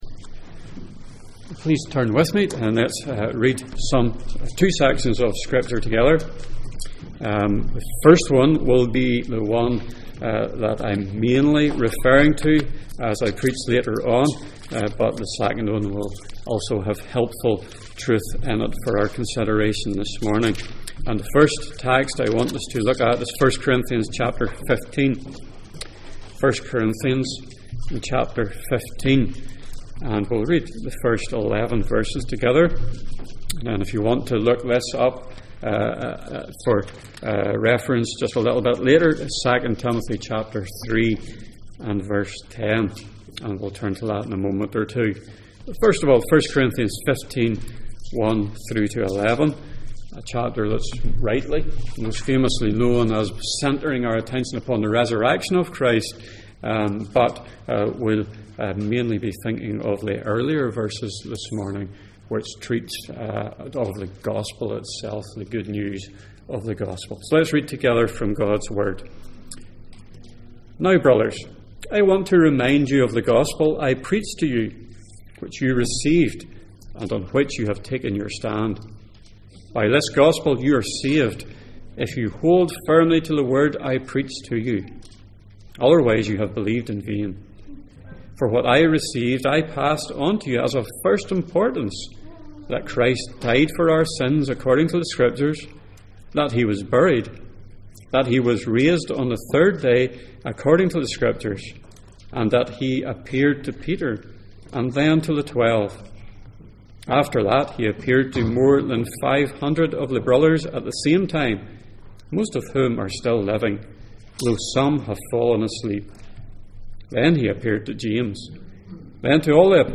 Passage: 1 Corinthians 15:1-11, 2 Timothy 3:10-17 Service Type: Sunday Morning %todo_render% « A great conversion Who may fit into heaven?